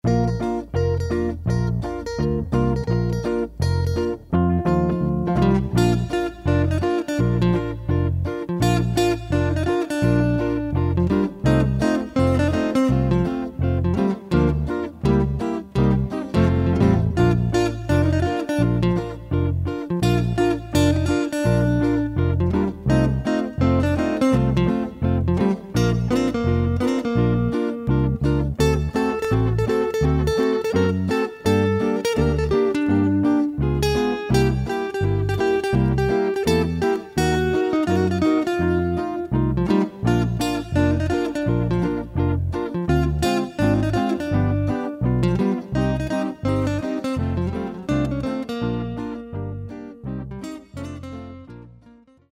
Fox-trot in stile anni '20.